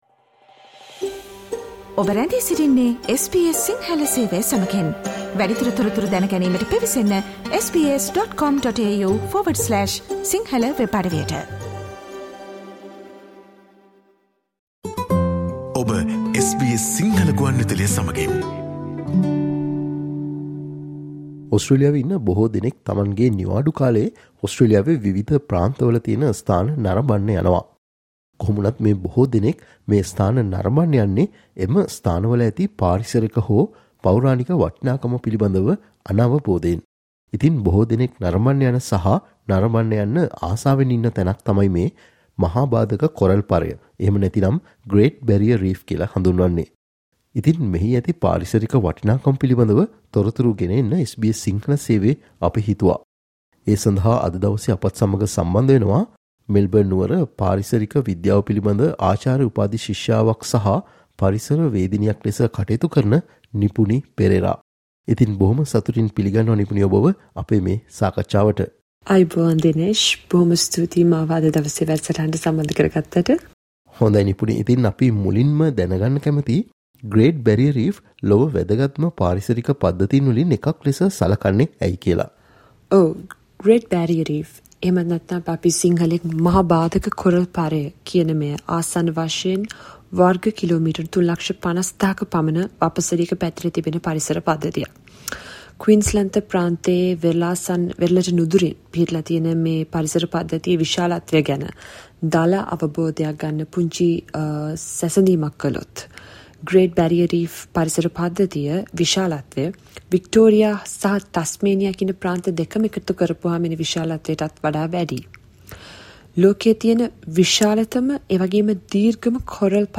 SBS Sinhala Interview on the Importance of the Great Barrier Reef ecosystem